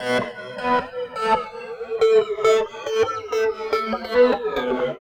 18 GUIT 1 -R.wav